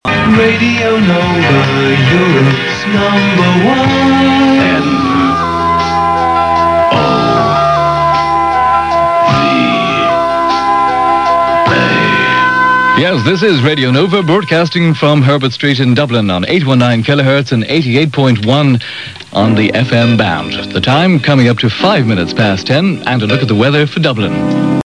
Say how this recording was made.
Later I heard Nova also on FM 88.1 MHz (